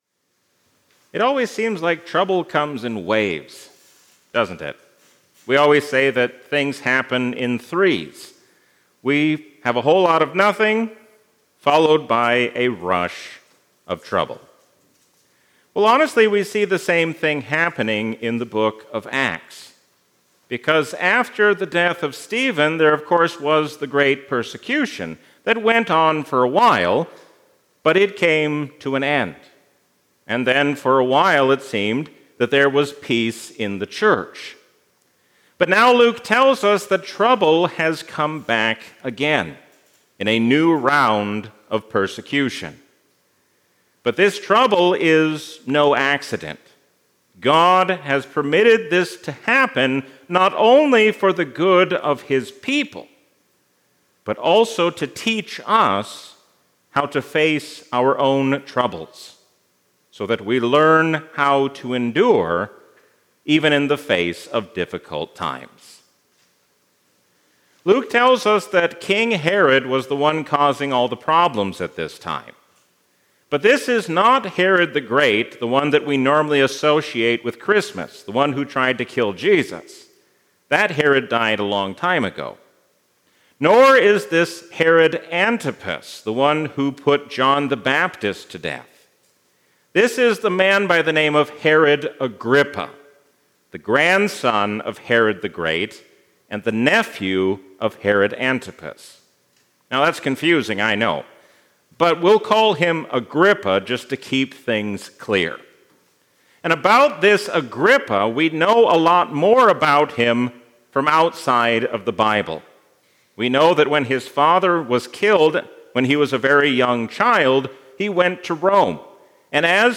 Sermons – St. Peter and Zion Lutheran